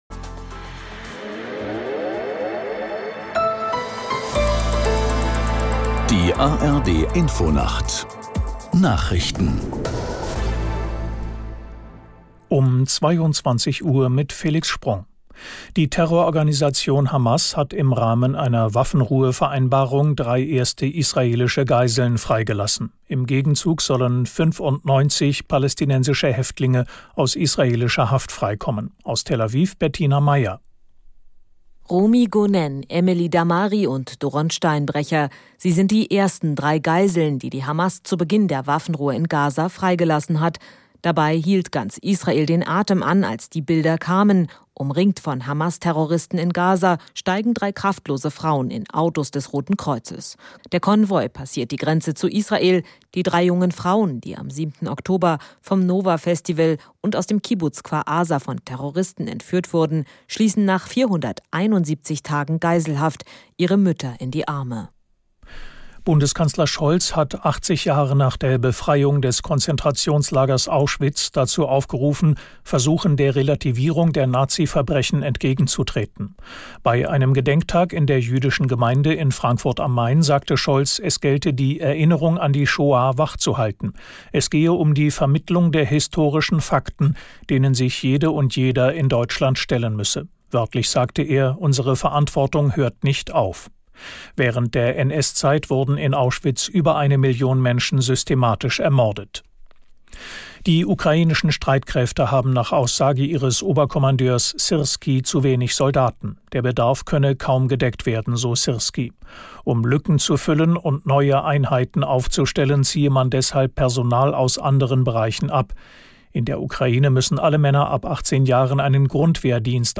1 Nachrichten 3:22 Play Pause 13m ago 3:22 Play Pause 나중에 재생 나중에 재생 리스트 좋아요 좋아요 3:22 Die aktuellen Meldungen aus der NDR Info Nachrichtenredaktion.